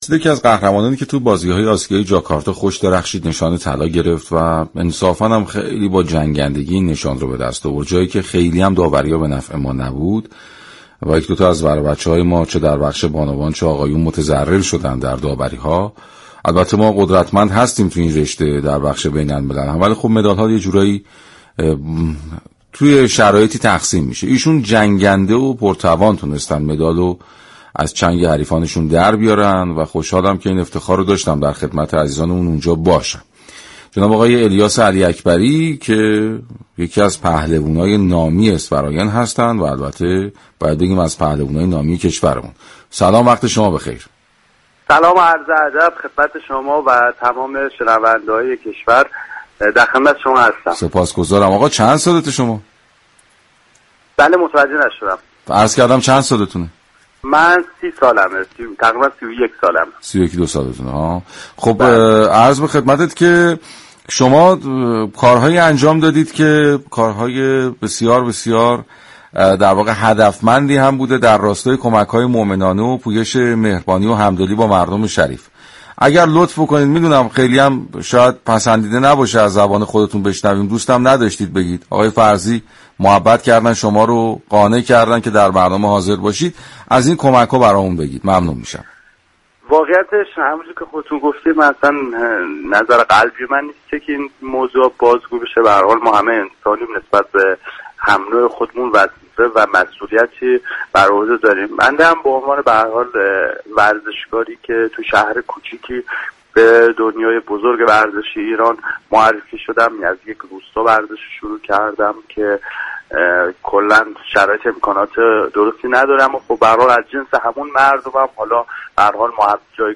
مصاحبه كامل